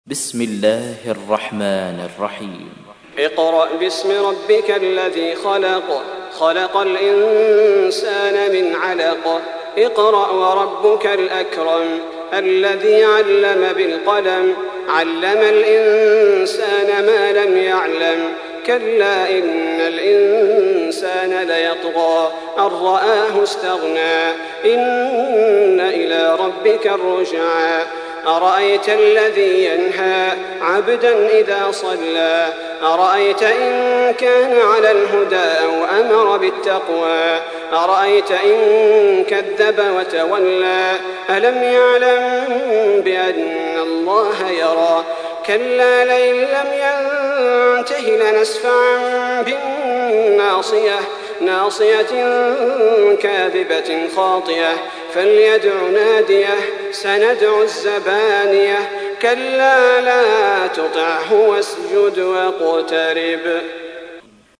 تحميل : 96. سورة العلق / القارئ صلاح البدير / القرآن الكريم / موقع يا حسين